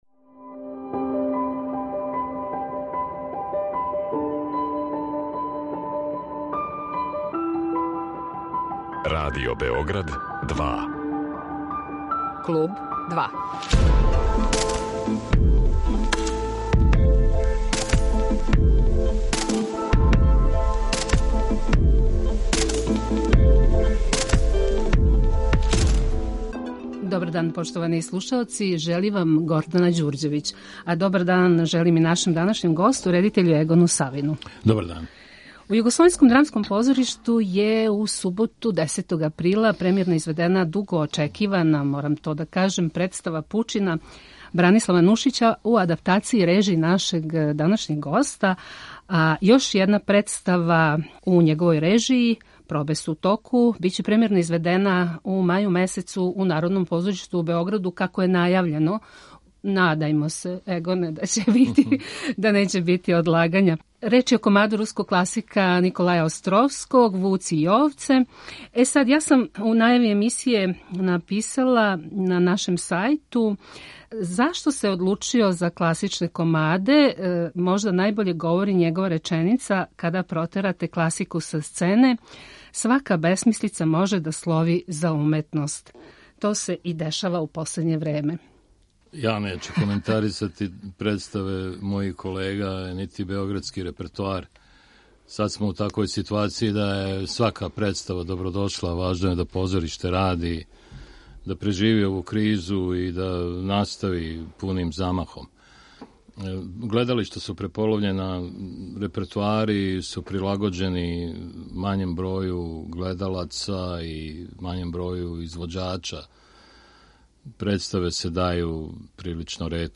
Гост Клуба 2 је наш чувени редитељ Егон Савин.